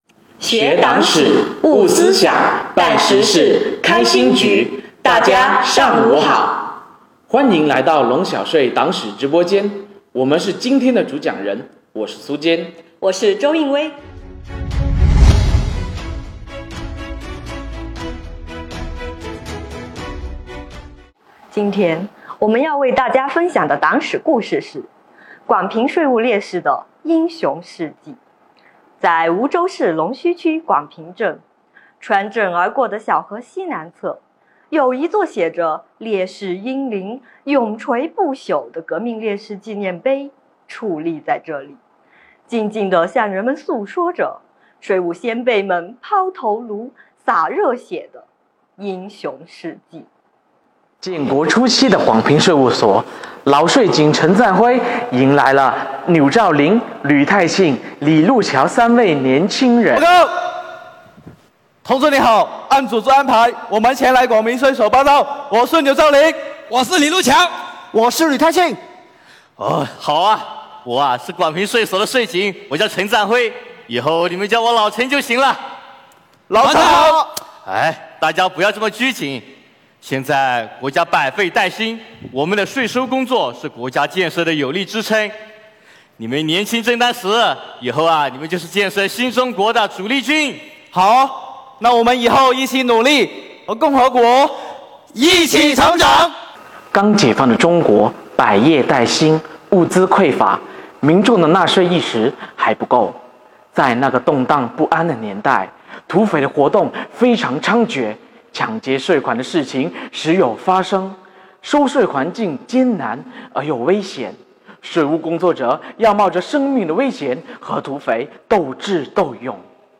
龙小税党史直播